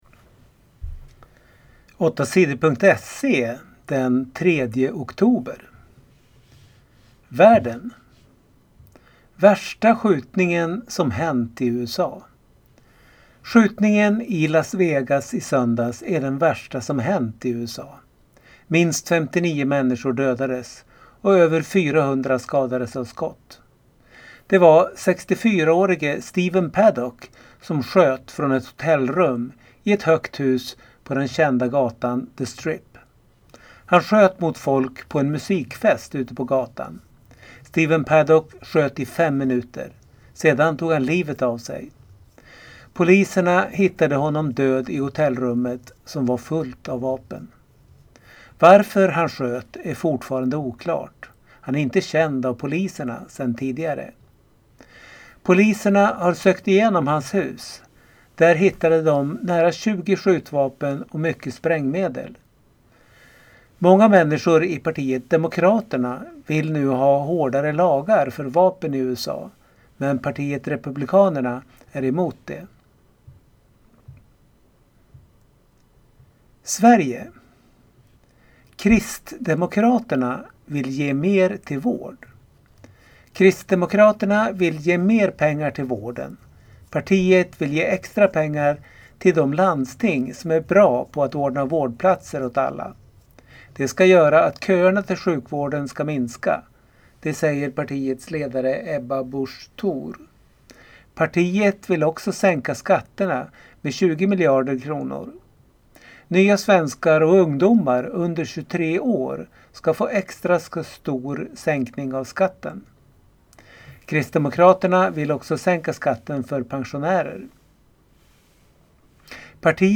Lyssna på nyheter från tisdagen den 3 oktober